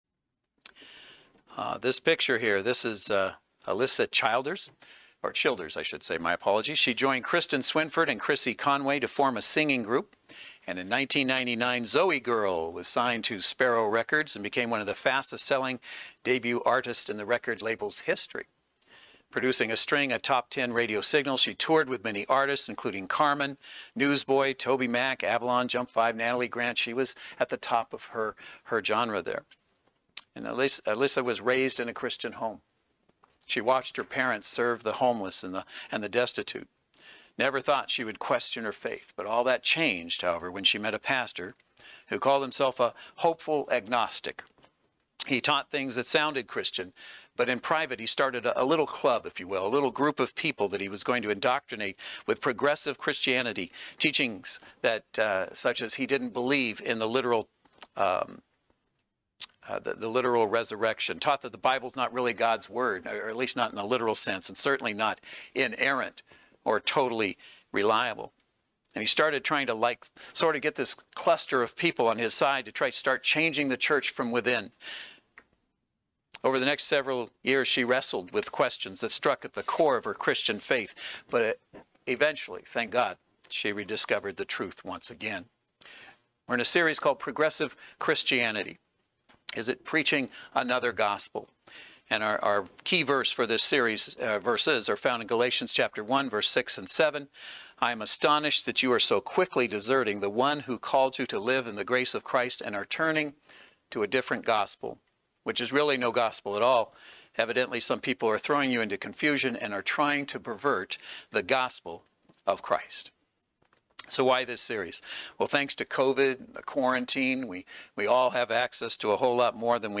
Service Type: Saturday Worship Service The greatest danger to Christians today comes not from outside the Church – but from within.